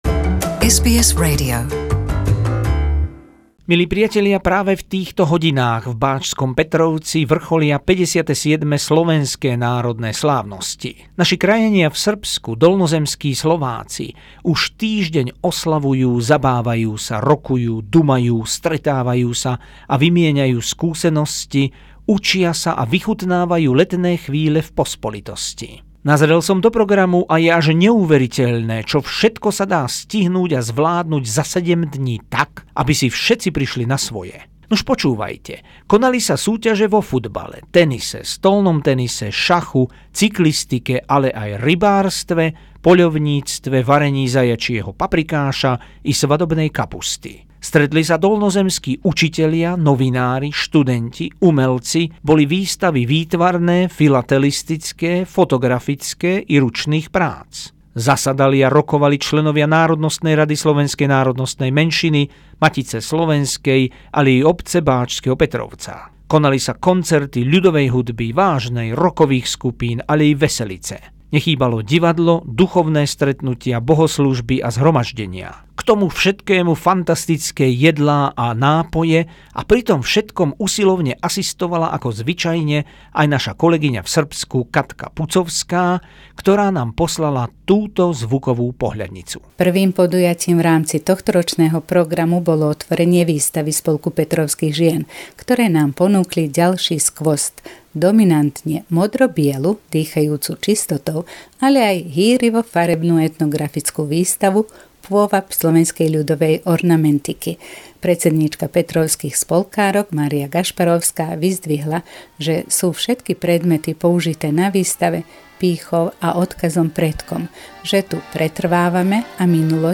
Slovenské národné slávnosti 2018 vo Vojvodine